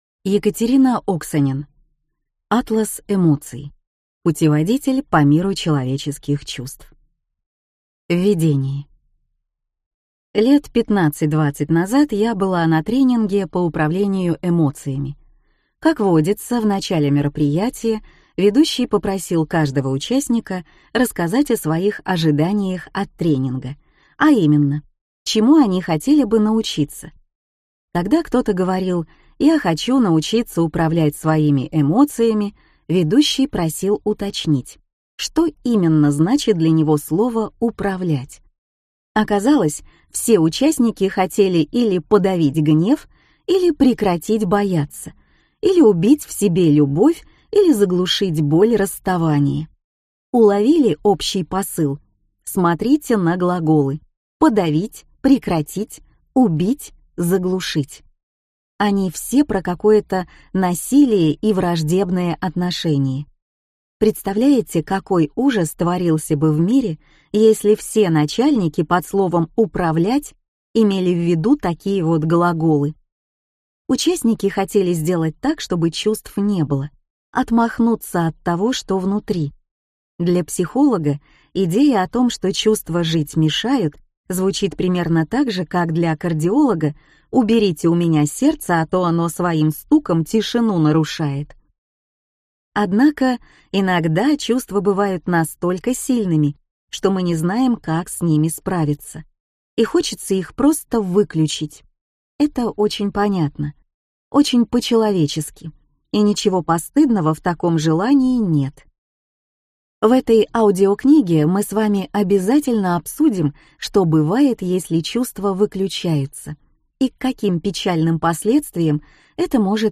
Аудиокнига Атлас эмоций. Путеводитель по миру человеческих чувств | Библиотека аудиокниг